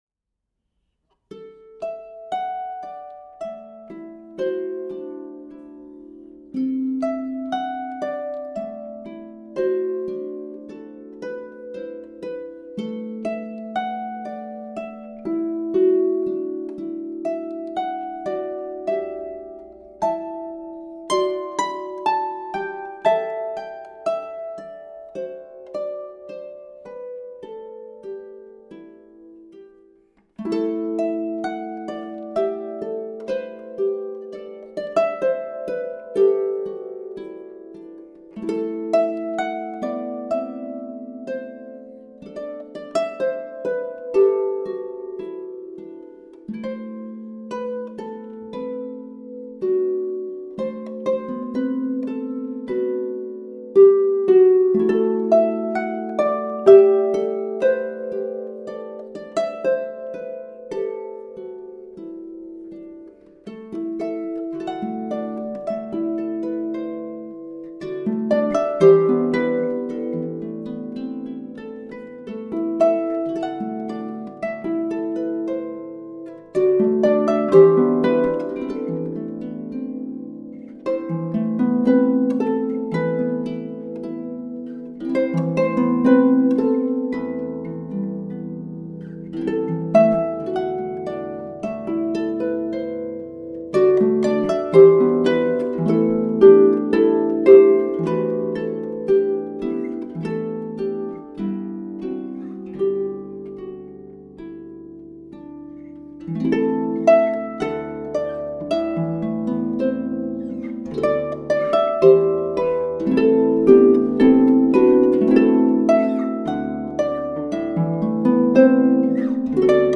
traditional 15th century French carol
solo lever or pedal harp